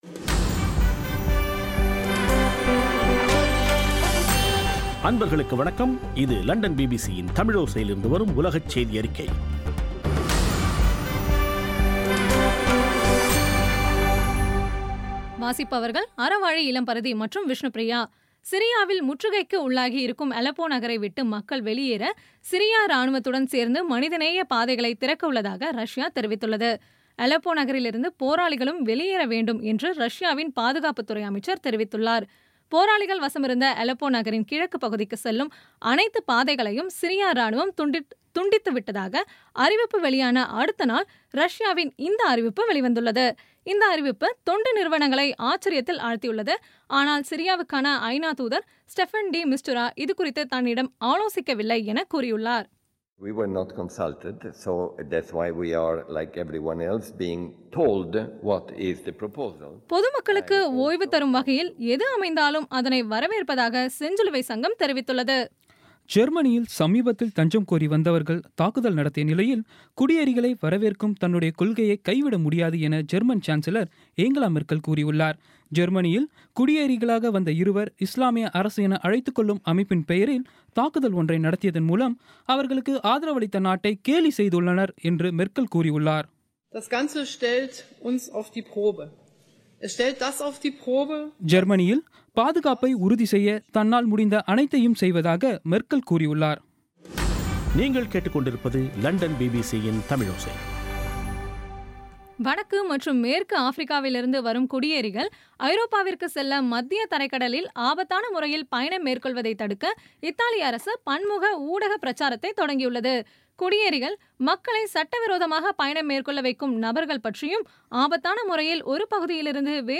இன்றைய (ஜூலை 28ம் தேதி ) பிபிசி தமிழோசை செய்தியறிக்கை